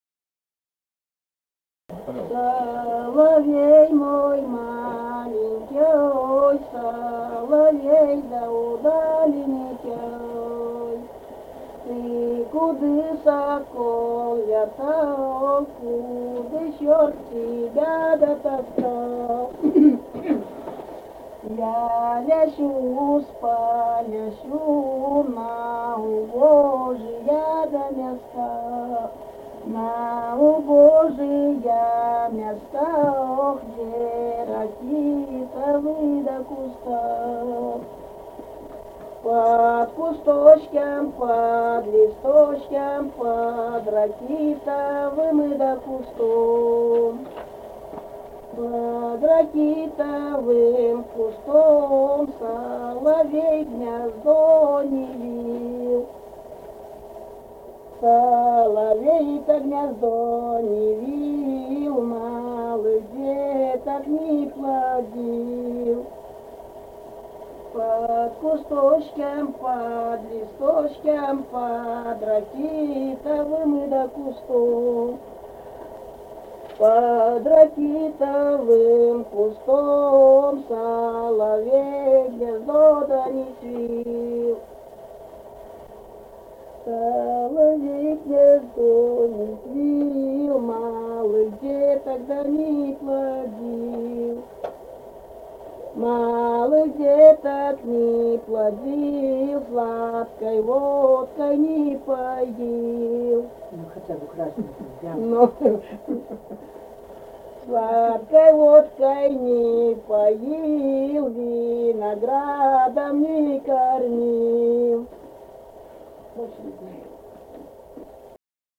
Русские песни Алтайского Беловодья 2 «Соловей мой маленький», «лужошная».
Республика Казахстан, Восточно- Казахстанская обл., Катон-Карагайский р-н, с. Язовая, июль 1978.